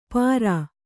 ♪ pārā